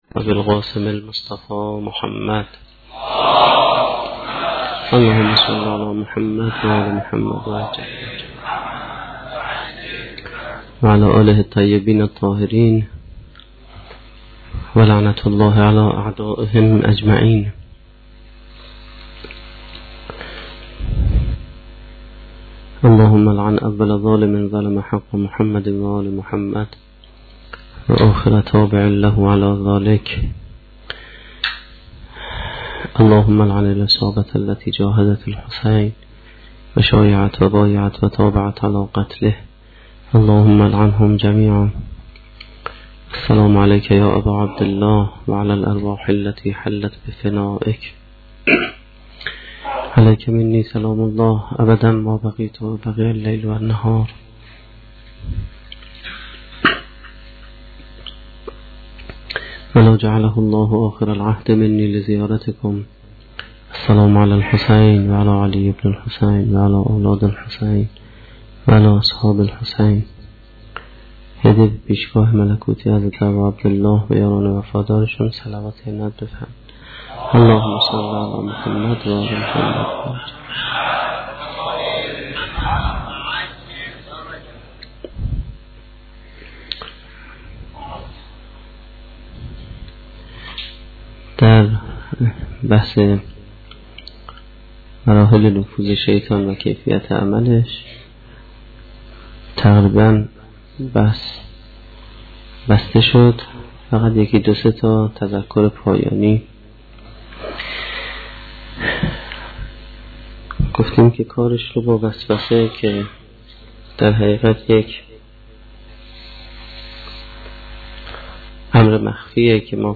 سخنرانی هشتمین شب دهه محرم1435-1392